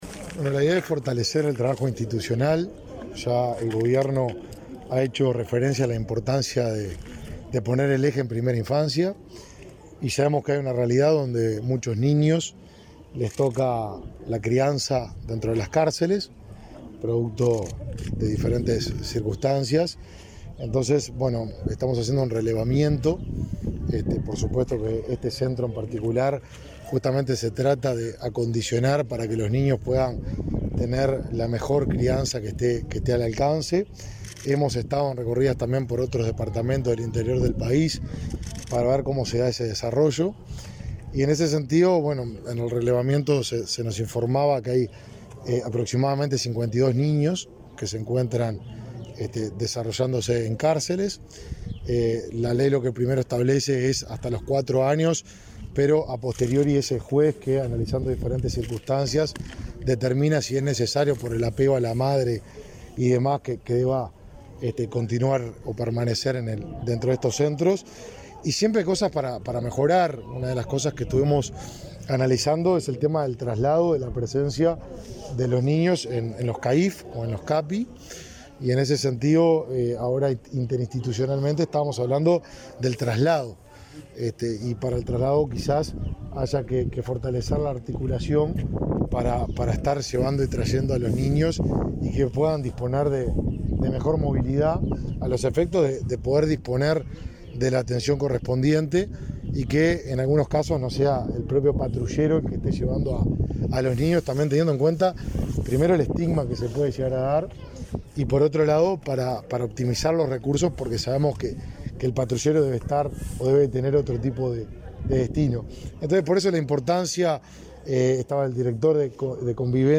Declaraciones a la prensa del ministro de Desarrollo Social, Martín Lema
Declaraciones a la prensa del ministro de Desarrollo Social, Martín Lema 26/08/2021 Compartir Facebook X Copiar enlace WhatsApp LinkedIn Autoridades del Mides realizaron, este 26 de agosto, una recorrida por la Unidad 9 del Instituto Nacional de Rehabilitación, en el que el ministerio implementa políticas dirigidas a mujeres privadas de libertad responsables de niños, a través del programa Uruguay Crece Contigo. Al finalizar, el ministro Lema habló con medios informativos.